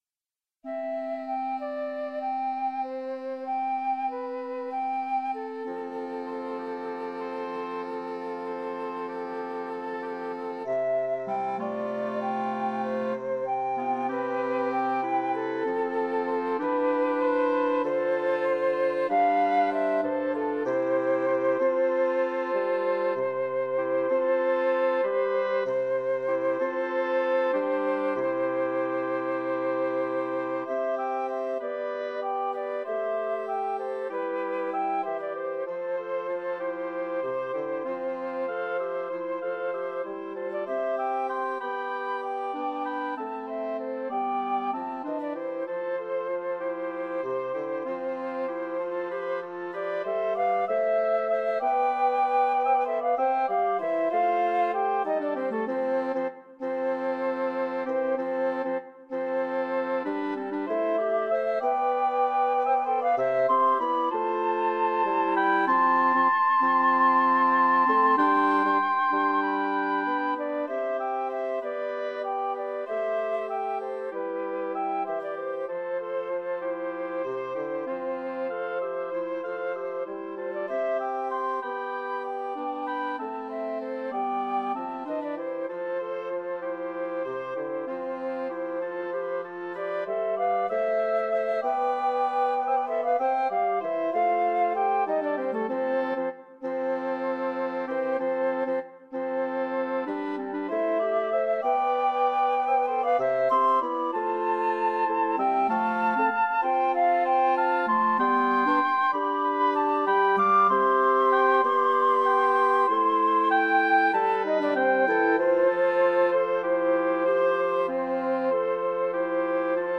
A simple, reflective piece for Woodwind Quartet.